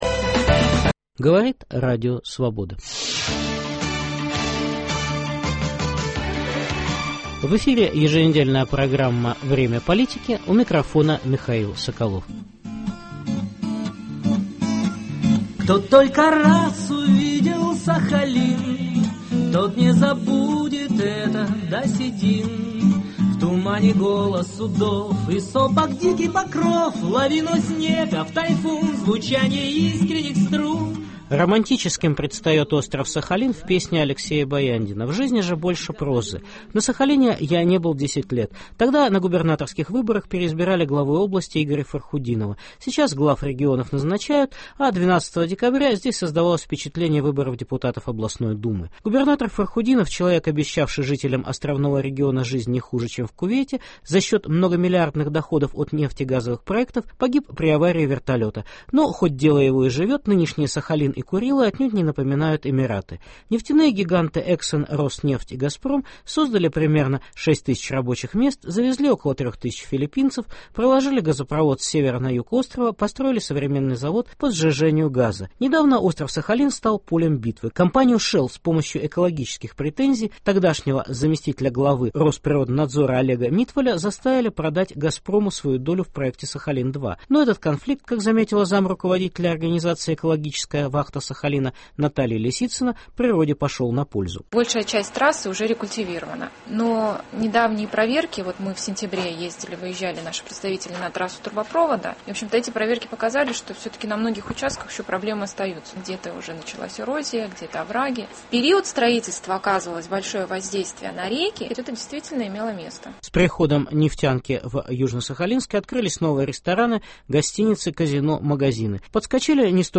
Специальный репортаж